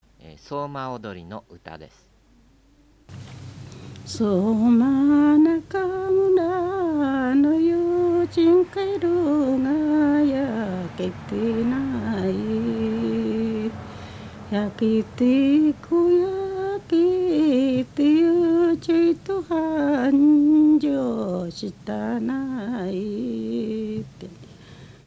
そうま踊りの唄 踊り歌
3_20_soumaodori.mp3